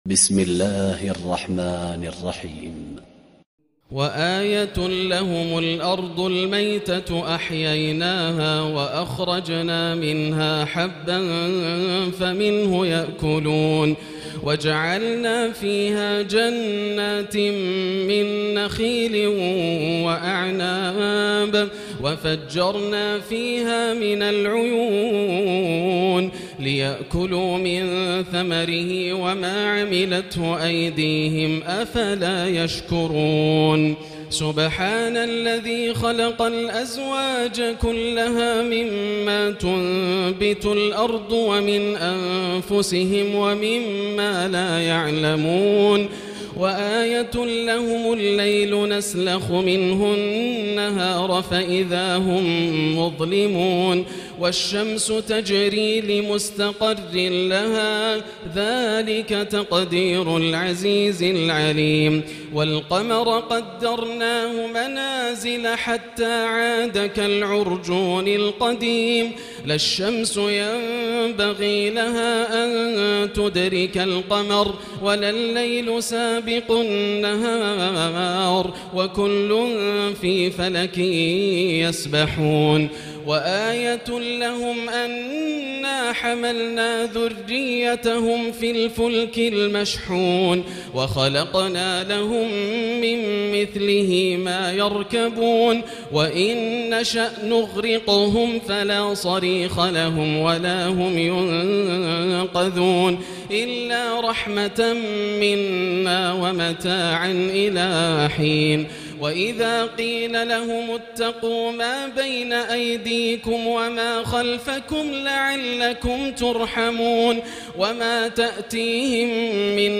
الليلة الثانية والعشرون - ما تيسر من سورتي يس من آية33-83 و الصافات من آية1-138 > الليالي الكاملة > رمضان 1439هـ > التراويح - تلاوات ياسر الدوسري